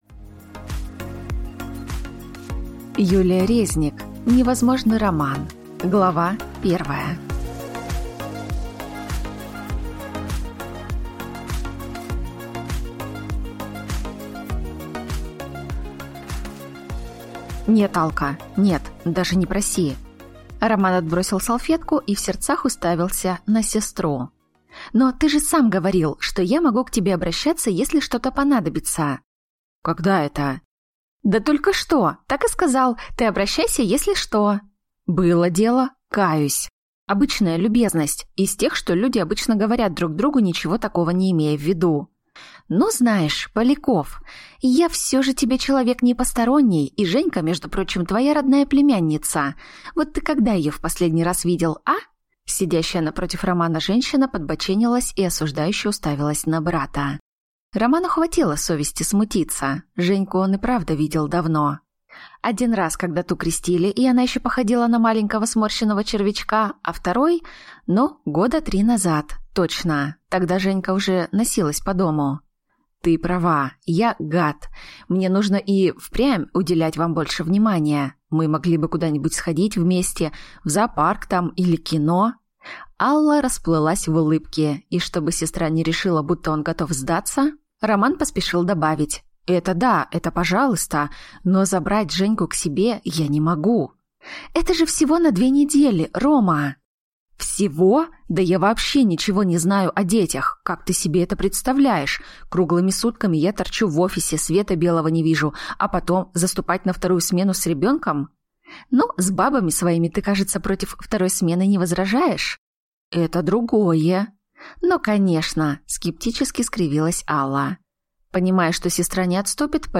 Aудиокнига Невозможный Роман Автор Юлия Резник Читает аудиокнигу